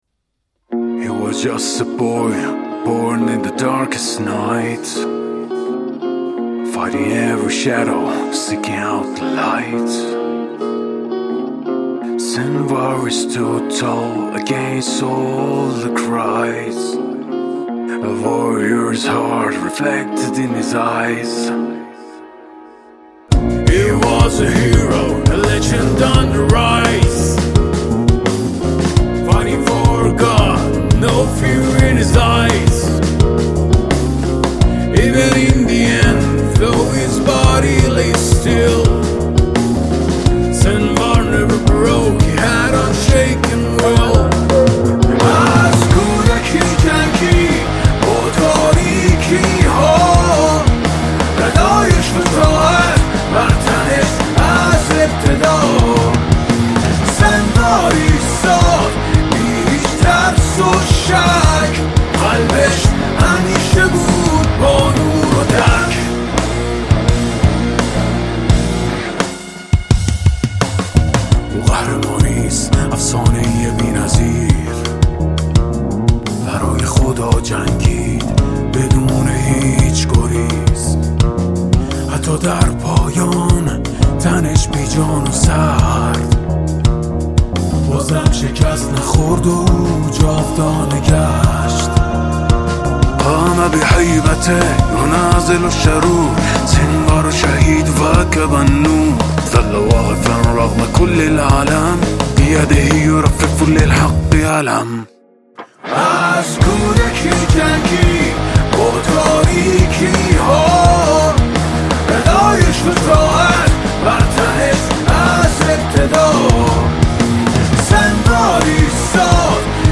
صدای گرم و پرقدرت